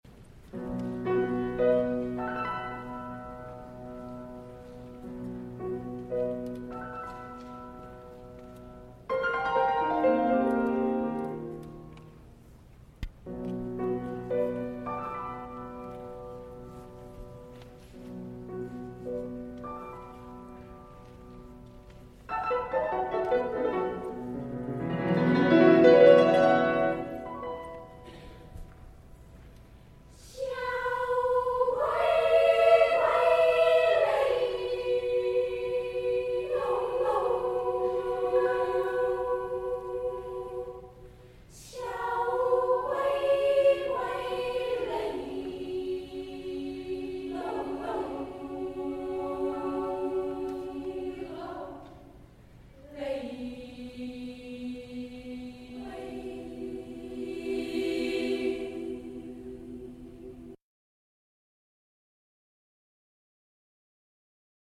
• Voicing: S, sa
• Accompaniment: a cappella